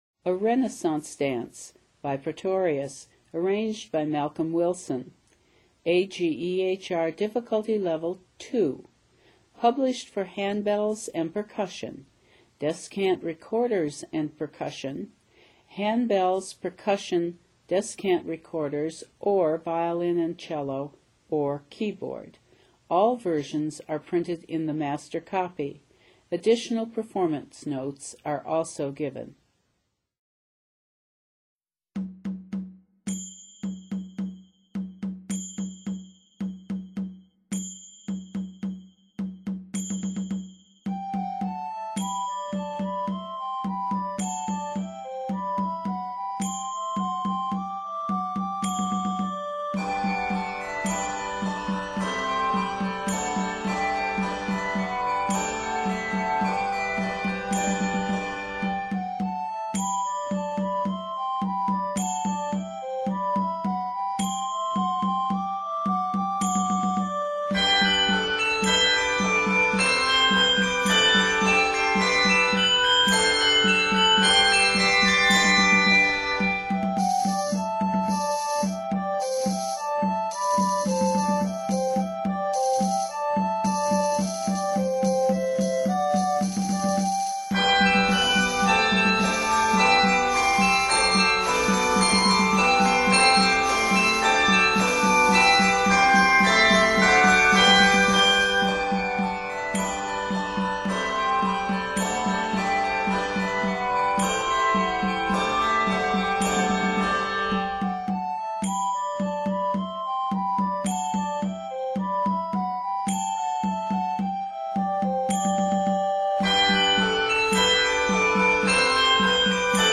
the lively dance music
Set in G Major, this work is 64 measures.